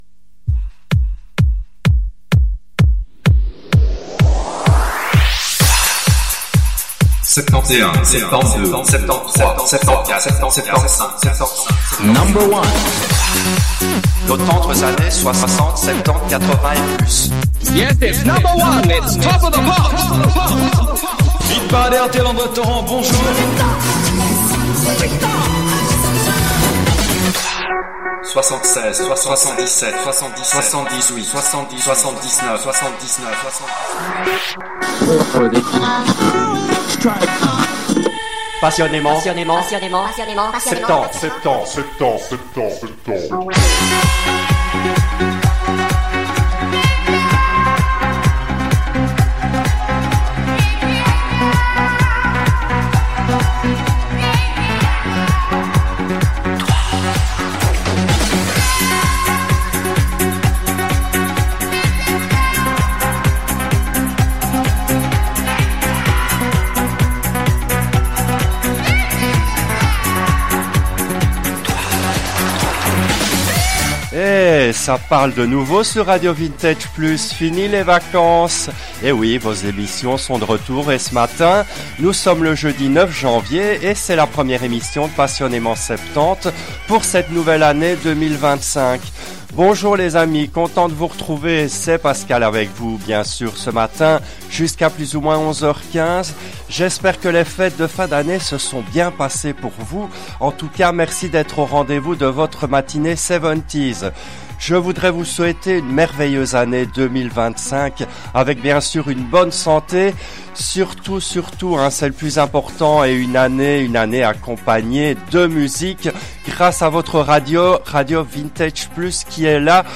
L’émission a été diffusée en direct le jeudi 09 janvier 2025 à 10h depuis les studios belges de RADIO RV+.